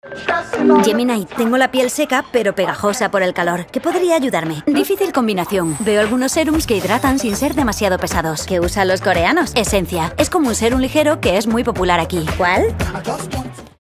Ich bin eine spanische Synchronsprecherin.
Enthusiastisch
Warm
Positiv